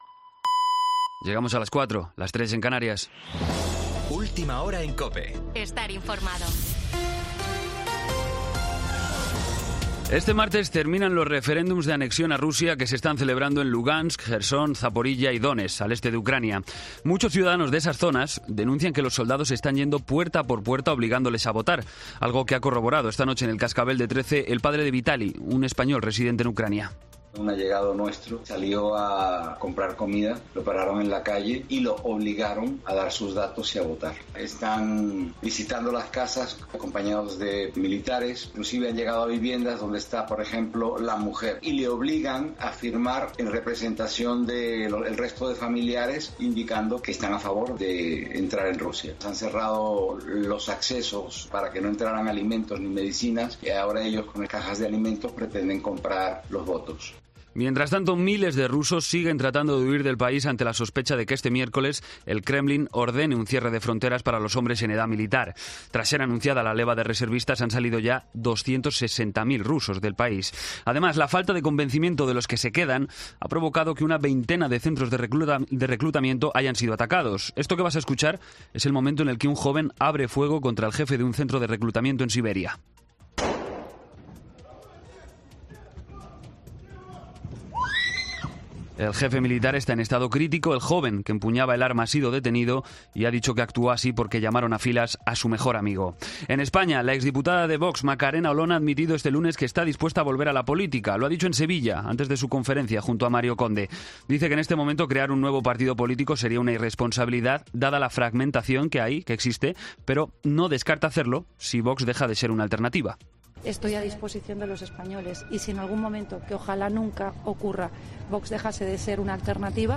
Boletín de noticias COPE del 27 de septiembre a las 04:00 hora